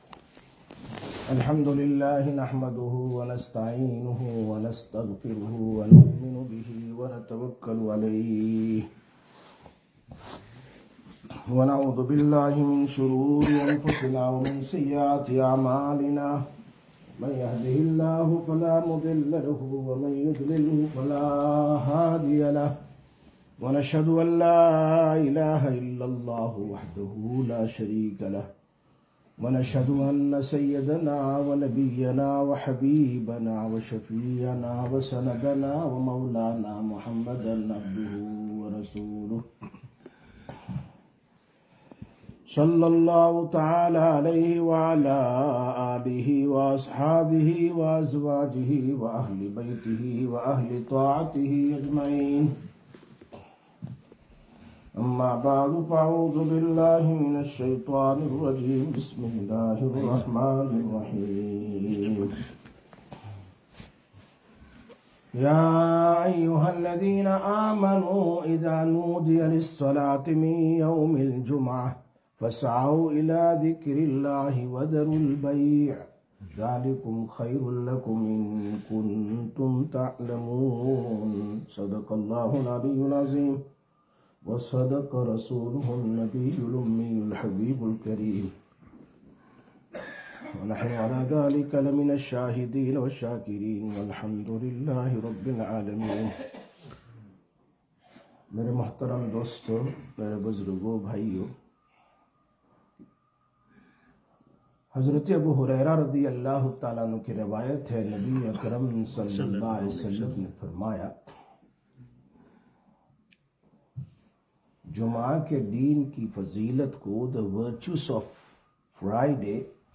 20/03/2026 Jumma Bayan, Masjid Quba